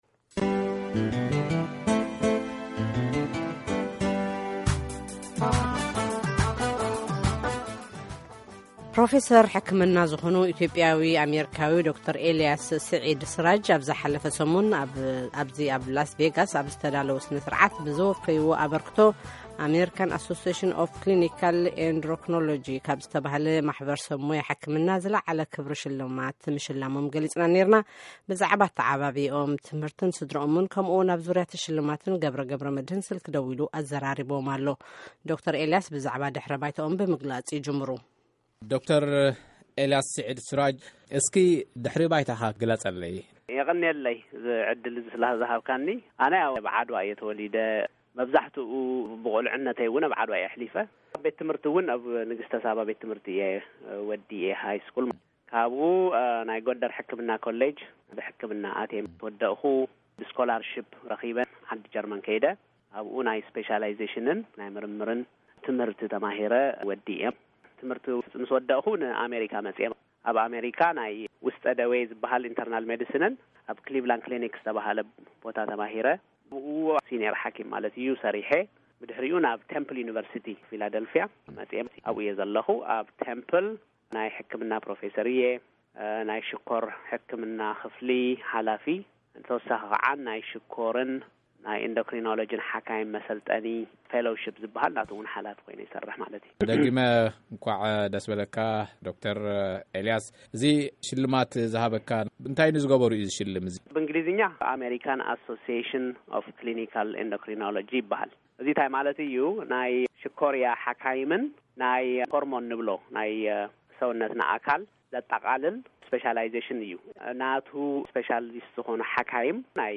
ቃለ-ምልልስ